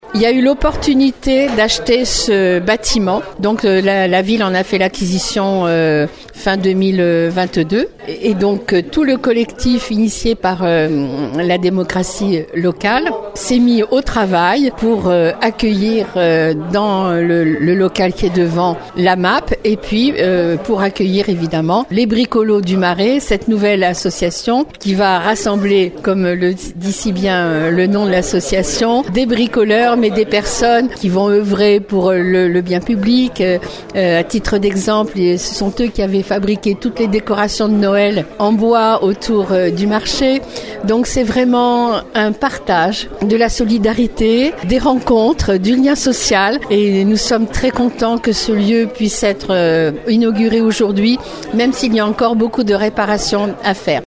Lors de l’inauguration cet après-midi avec la maire de Marennes-Hiers-Brouage Claude Balloteau qui a coupé le ruban.